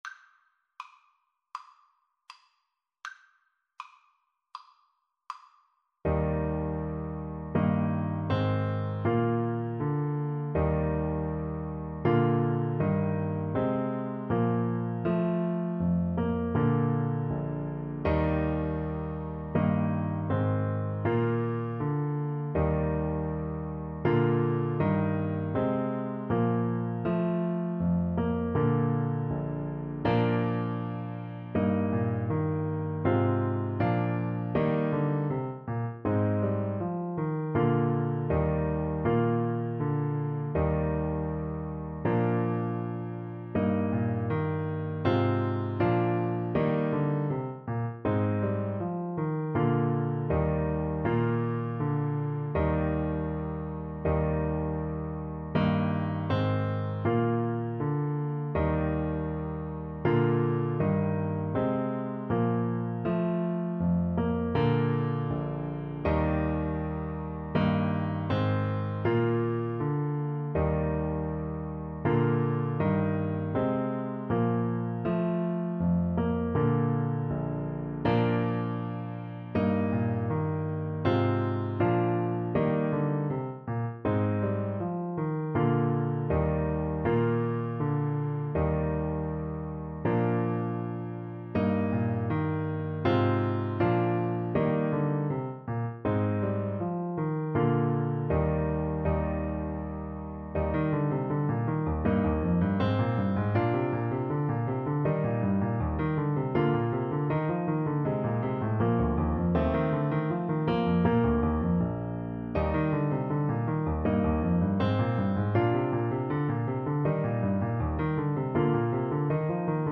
Allegretto =80
4/4 (View more 4/4 Music)
Classical (View more Classical Saxophone Music)